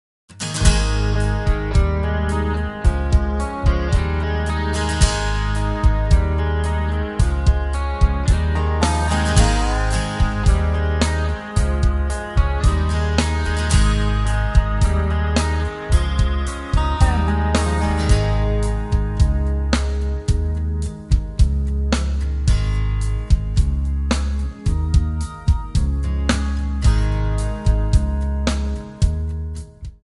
Eb
MPEG 1 Layer 3 (Stereo)
Backing track Karaoke
Country, 1990s